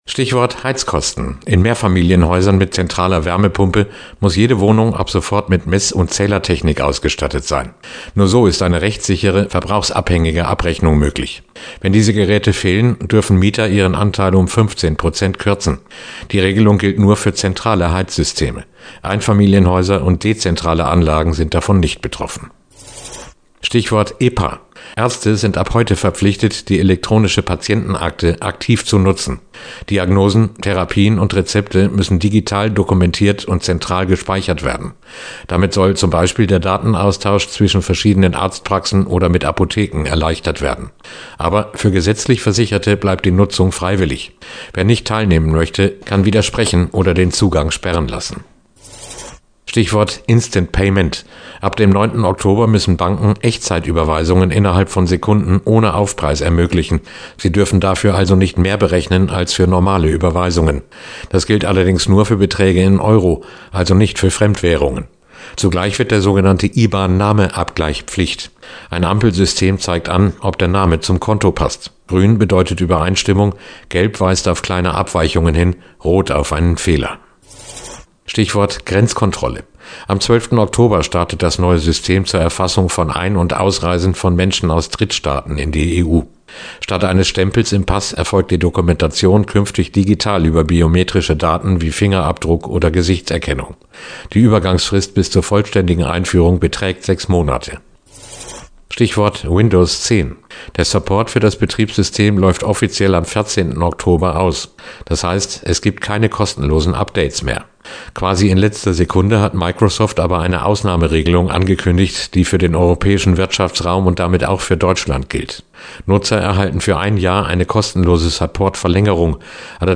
Aufsager-Neu-ab-Oktober.mp3